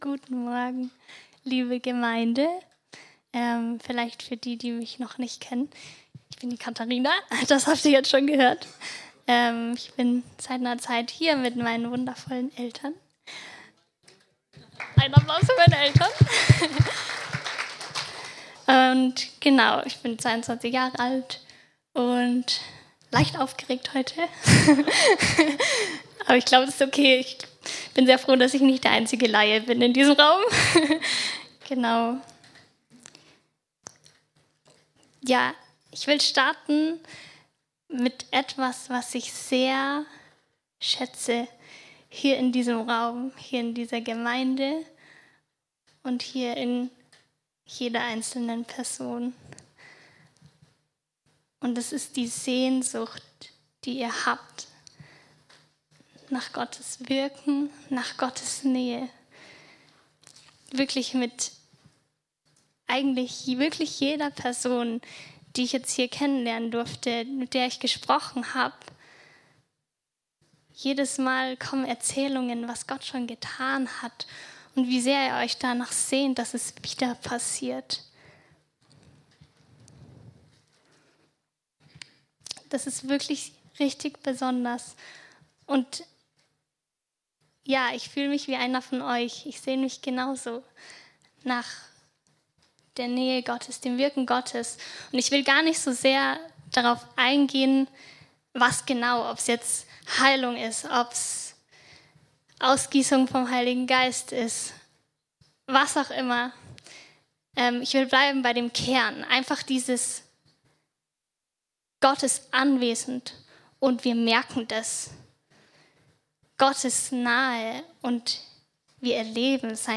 Hier findest du alle Predigten aus dem CZM